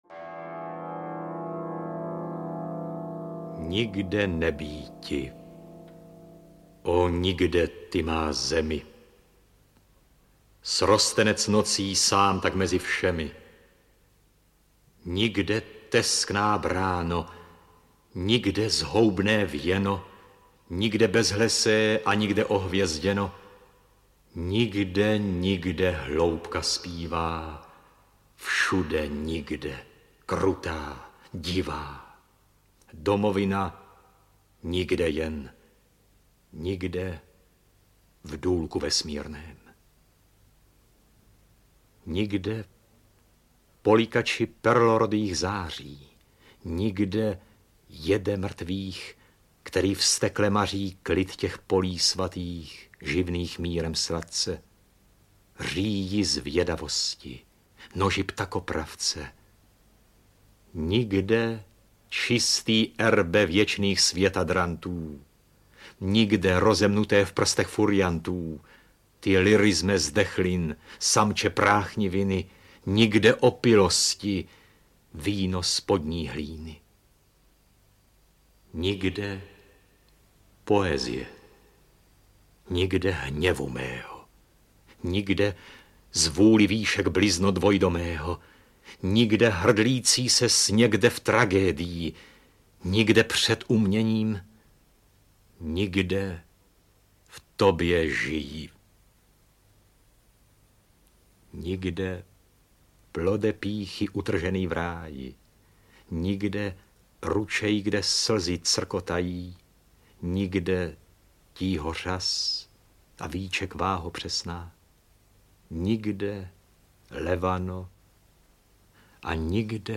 Básnický portrét Františka Halase audiokniha
Ukázka z knihy
• InterpretOtakar Dadák, Miroslav Doležal, Vlasta Fialová, Radovan Lukavský, Otakar Brousek st.